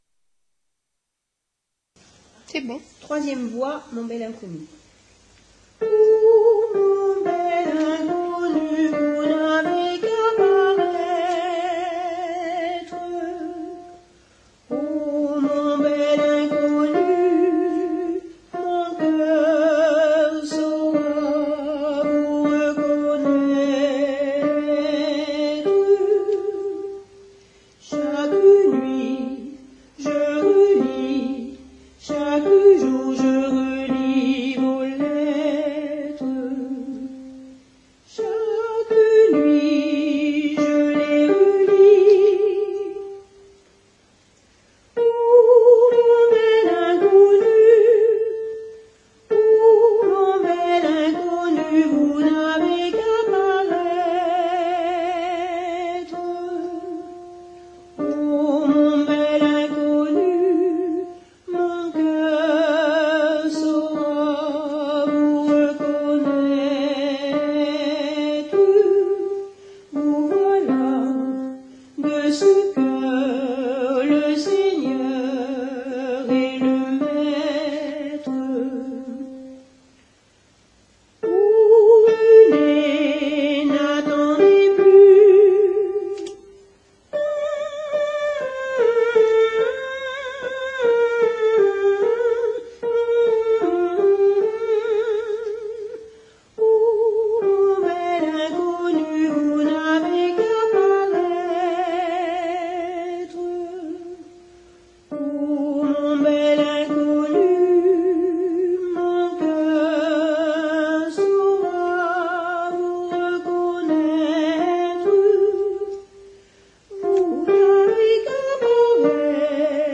alti 2